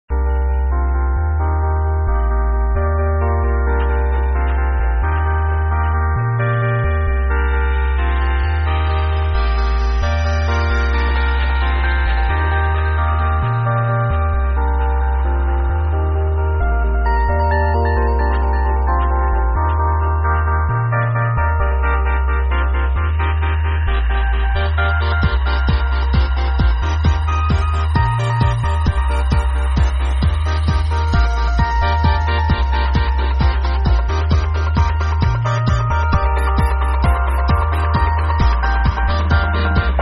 dreamy tune from 2002...
sorry about the shitty quality...anyone recognize this?